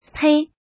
怎么读
tēi tuī
tei1.mp3